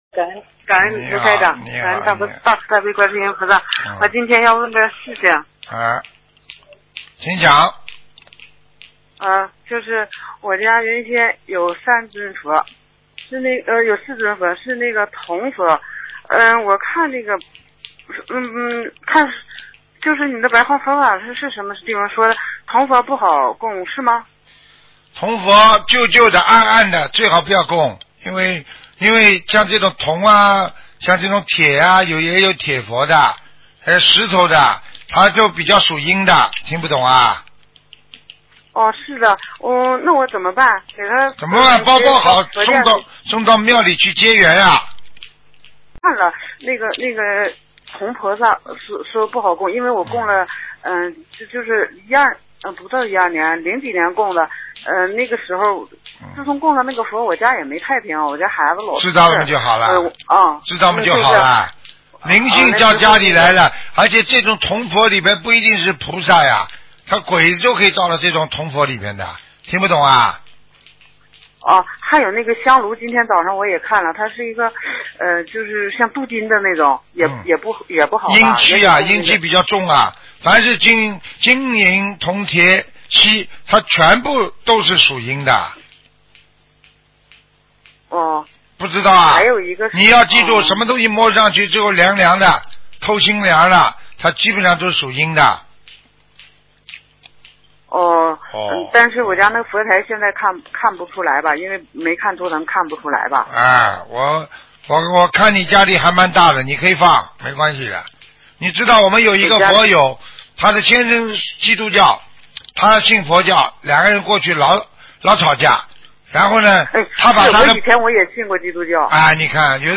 女听众